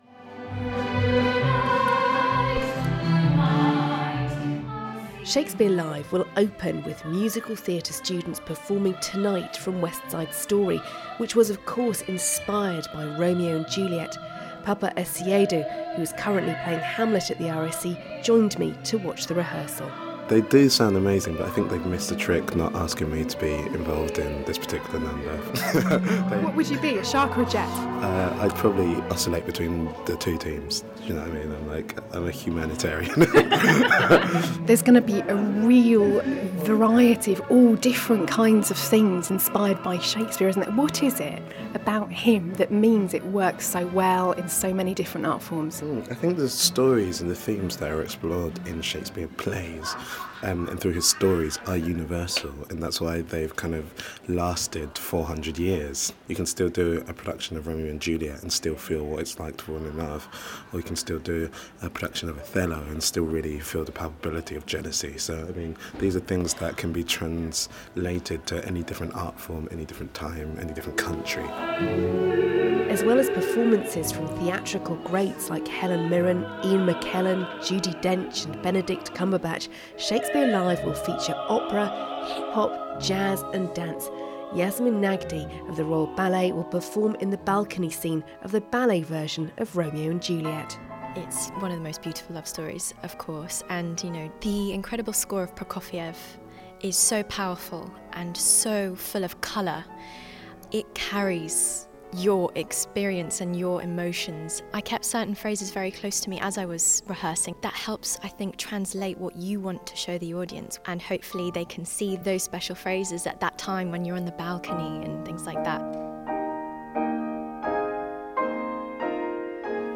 But what makes the Bard so brilliant and still so relevant 400 years on from his death? I spoke to some of the performers gathered to celebrate his life and legacy.